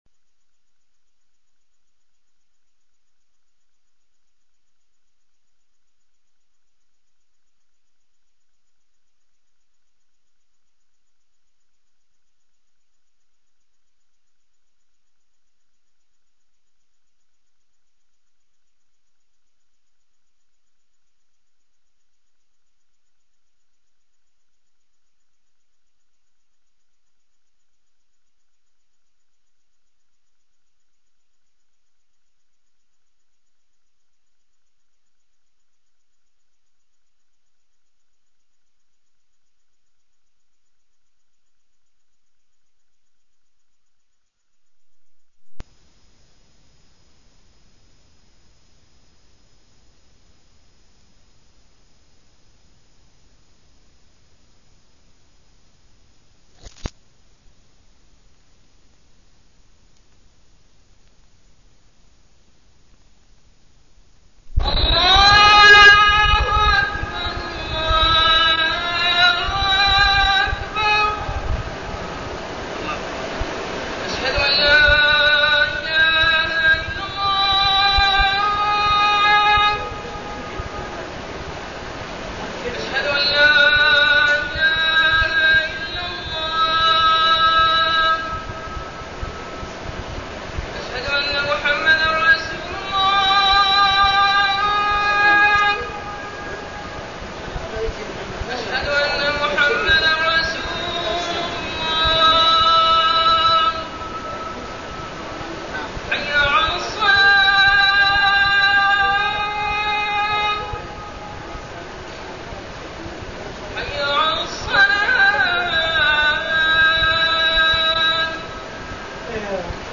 تاريخ النشر ١٨ صفر ١٤١١ هـ المكان: المسجد الحرام الشيخ: محمد بن عبد الله السبيل محمد بن عبد الله السبيل فضل الجهاد في سبيل الله